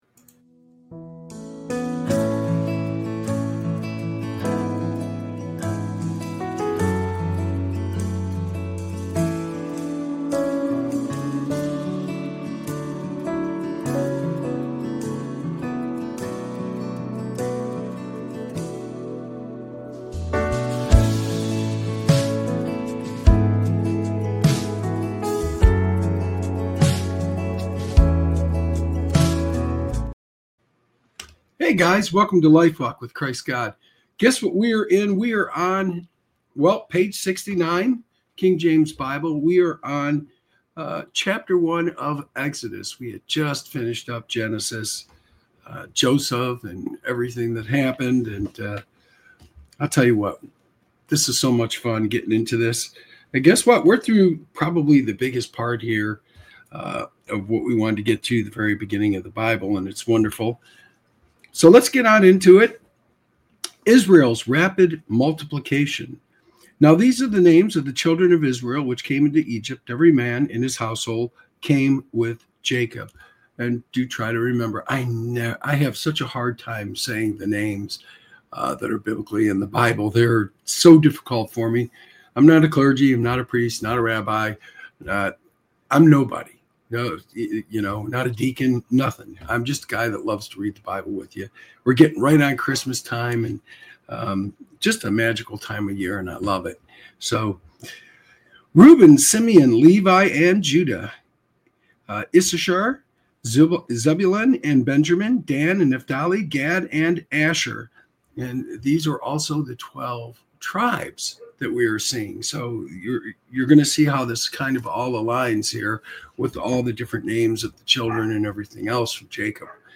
This show offers a reading into the teachings of Jesus Christ, providing insights into the Bible. Through engaging readings, heartfelt testimonies, and inspiring messages, Lifewalk with Christ God aims to: Strengthen faith: Explore the power of prayer, worship, and devotion.